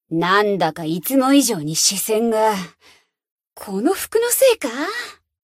BA_V_Neru_Bunny_Cafe_Monolog_1.ogg